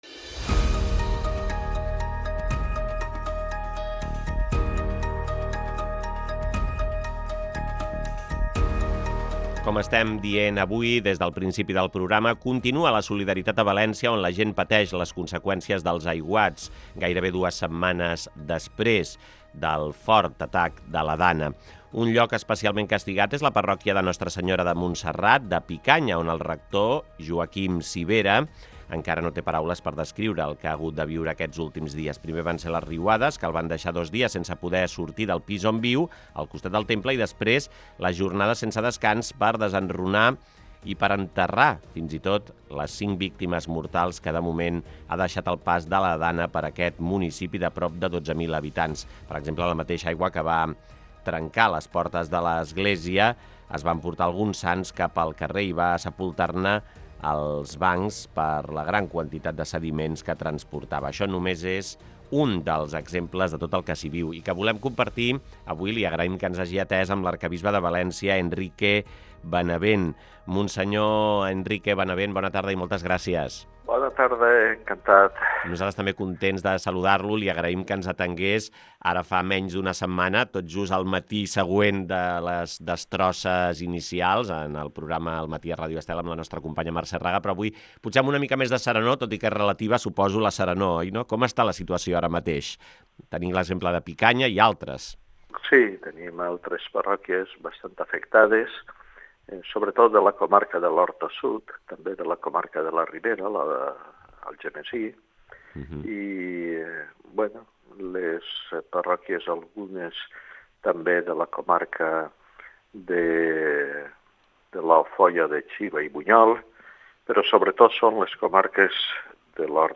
Escolta l'entrevista amb l'arquebisbe de València, Enrique Benavent